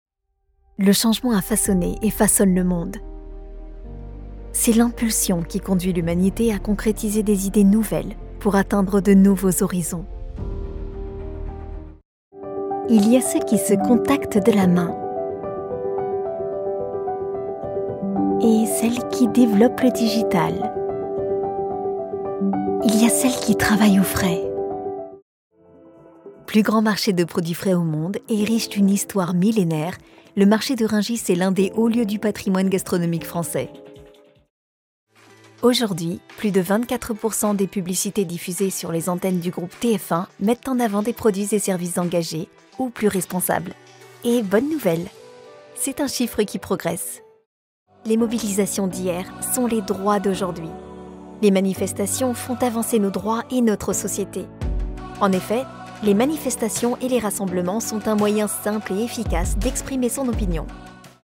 Comédienne voix off bilingue: Un sourire dans une voix…
Institutionnel
Ma voix peut être rassurante, convaincante, chaleureuse, sensuelle et séduisante, mais aussi pétillante, enjouée et avec une tonalité enfantine.